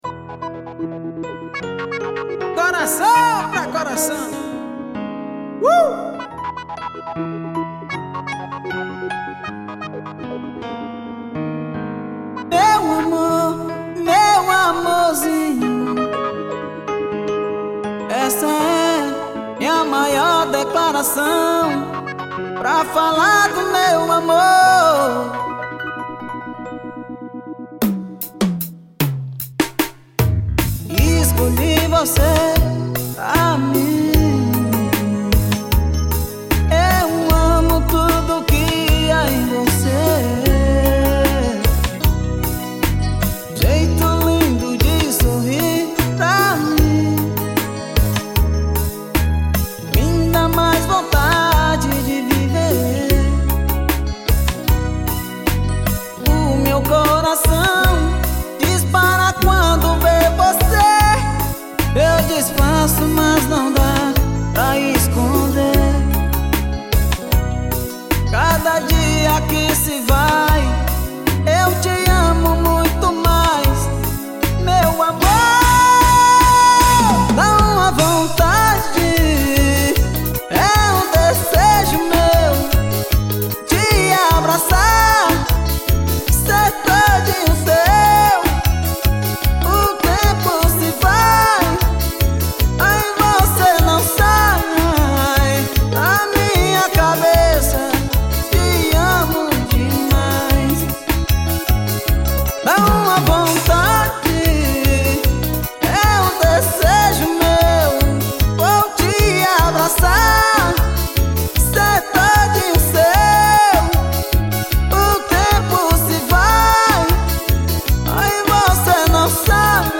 audio do dvd.